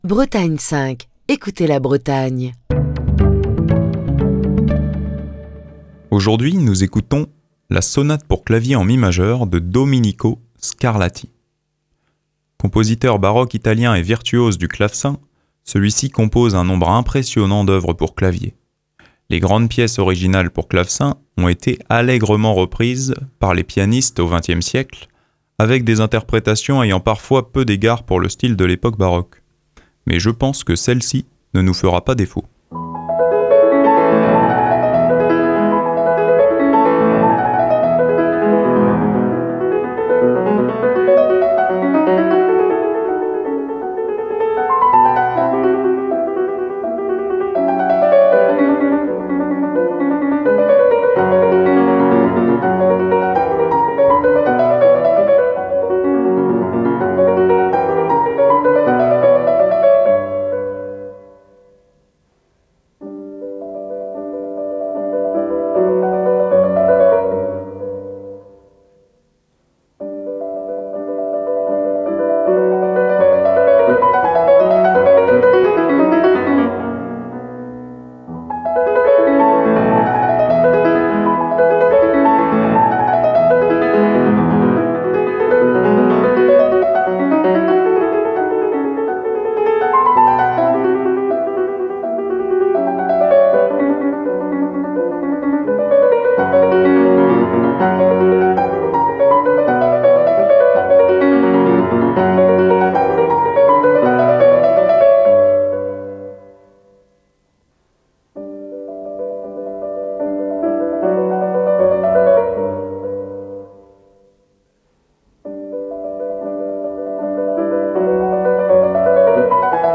clavier en mi majeur
interprétée par Zhu Xiao-Mei